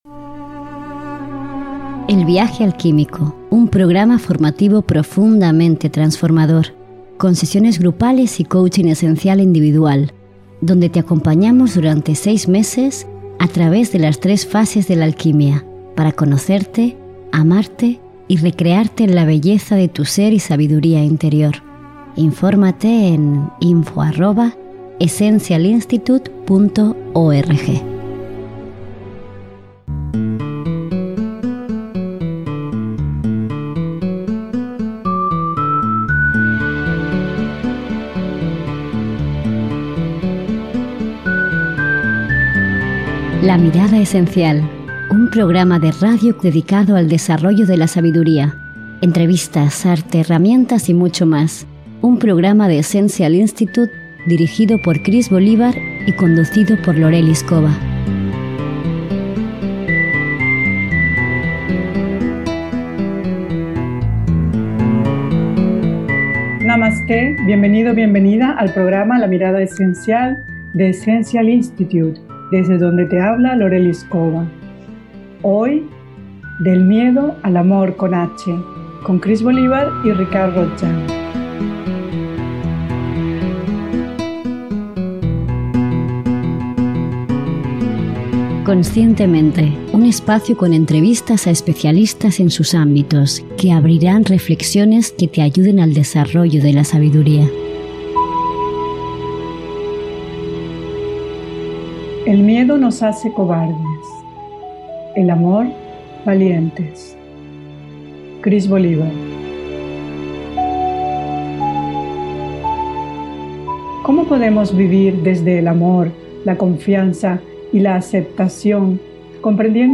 profesor de filosofía y psicología budista y de meditación y yoga.